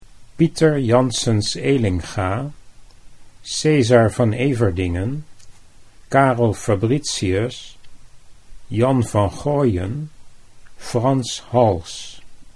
How to Pronounce the Names of Some Dutch Painting Masters